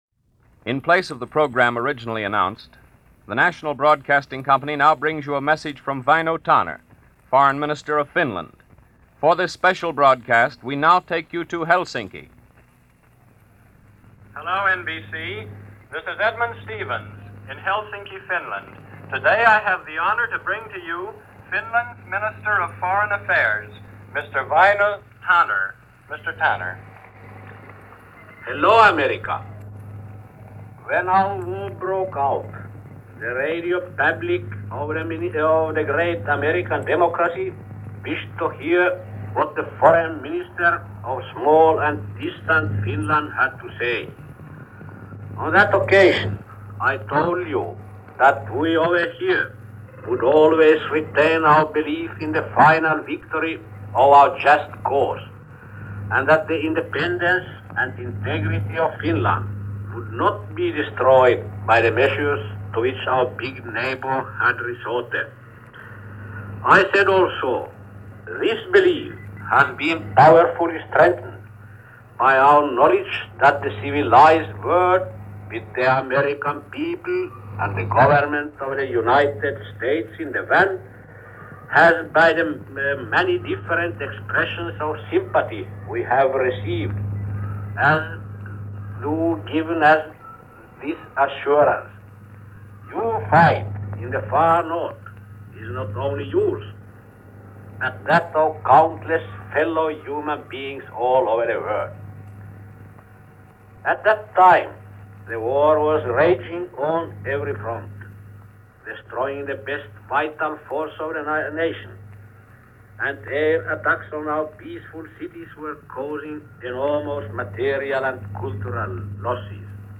So a truce was declared on March 13, 1940. Foreign Minister Väniö Tanner went before microphones to give the news to the Finnish people, and later addressed the English-speaking world in a Shortwave broadcast on March 15, 1940.
Here is that address by Väinö Tanner, as it was broadcast to the U.S. via the NBC Blue Network on March 15, 1940.